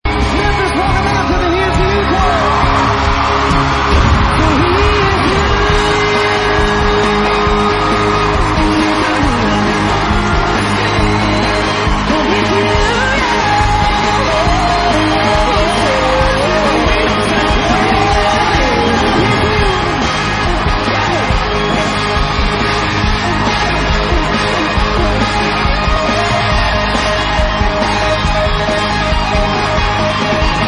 • Country Ringtones